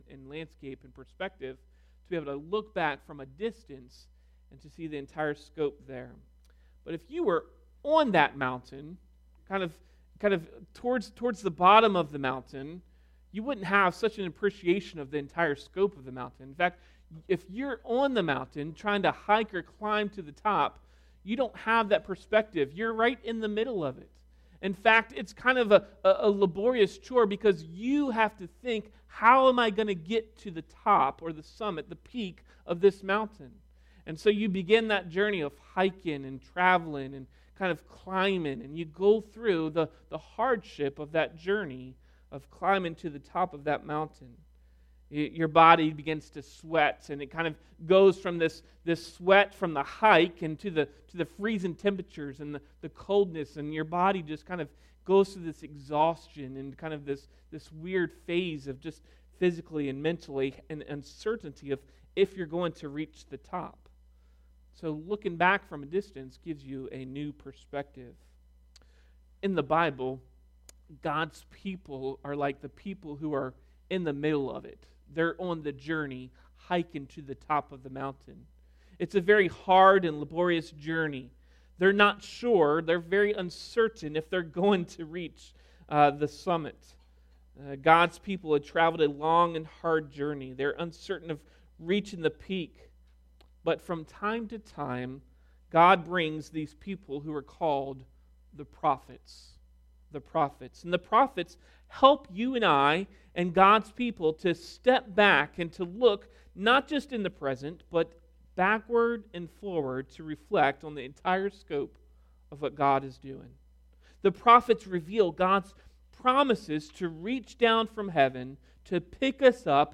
30novsermon.mp3